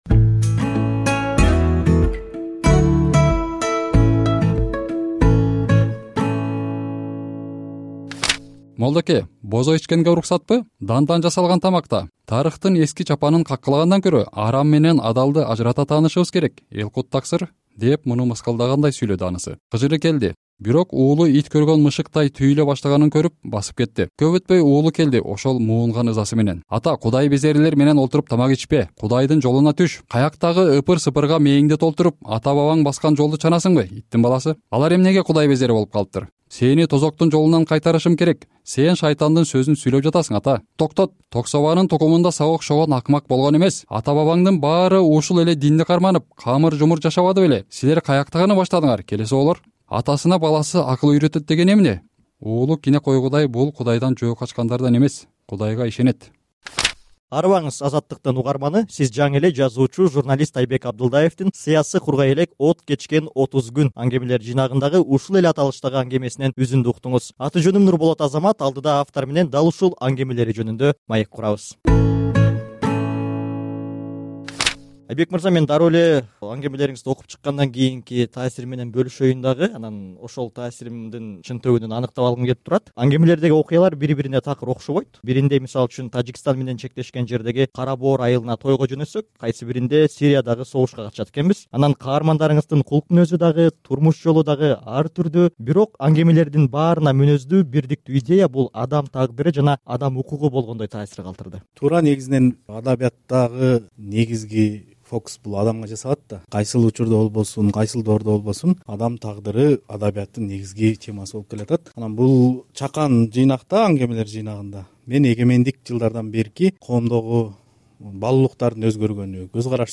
Биз автор менен анын чакан аңгемелериндеги айтылган ойлор, негизги идеялар жөнүндө маек курдук.